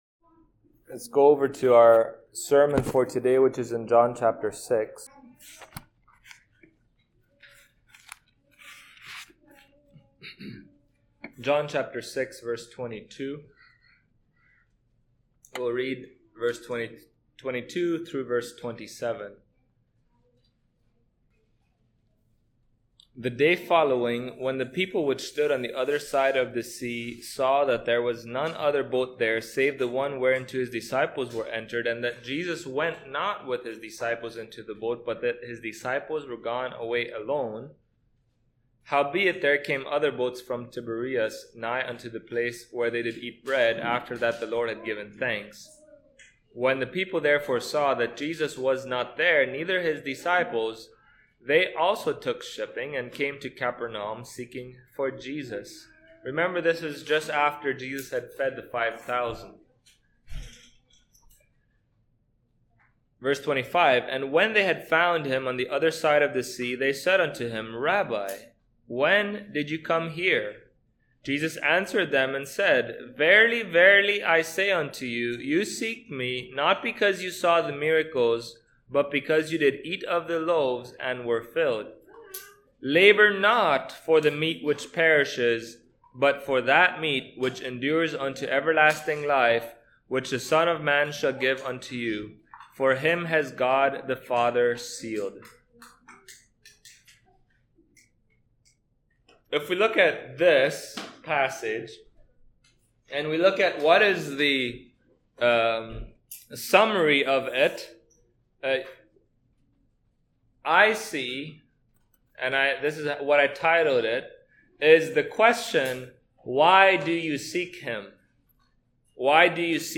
John Passage: John 6:22-27 Service Type: Sunday Morning Topics